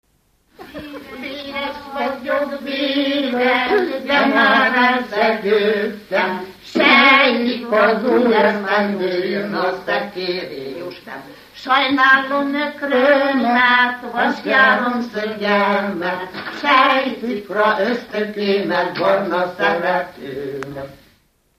Dunántúl - Fejér vm. - Perkáta
Stílus: 5. Rákóczi dallamkör és fríg környezete
Szótagszám: 6.6.7.6
Kadencia: 4 (1) 1 V